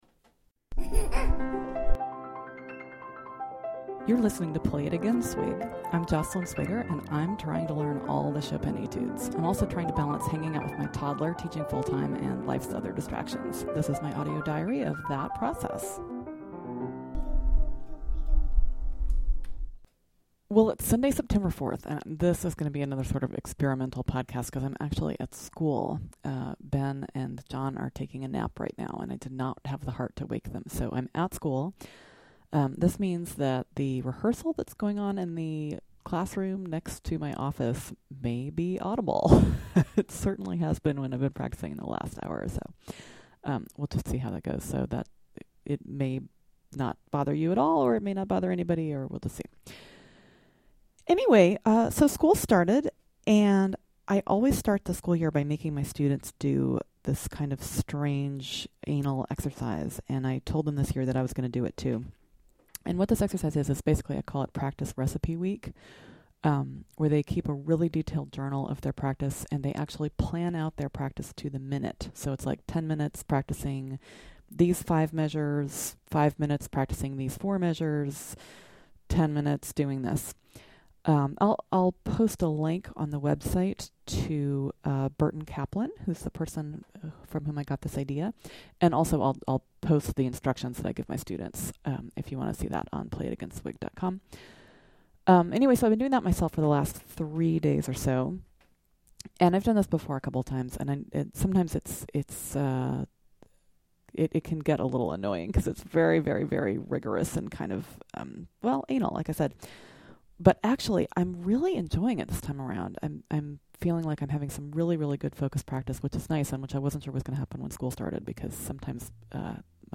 Tools for focused practice; strategies for Chopin 25/11; a mediocre performance of 25/2; bits of 25/3 and 25/5; an attempt at improvisation (and a confession that I find improvising a little scary).